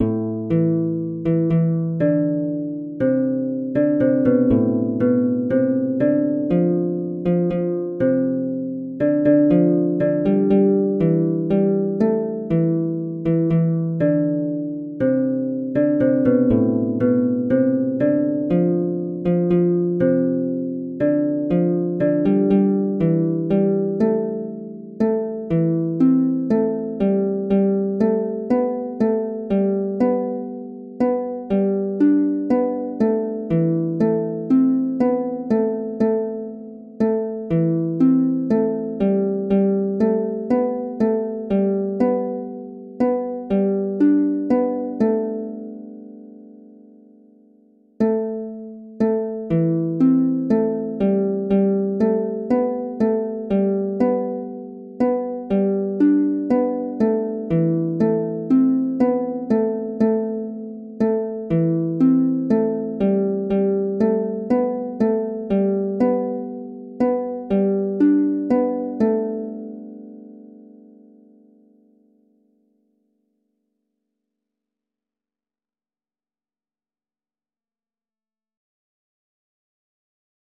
Jewish Folk Song for Shabbat
A minor ♩= 40 bpm